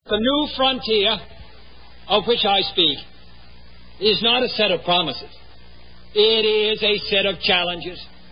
Click on picture for a President Kennedy sound clip — "The New Frontier" — 10 seconds long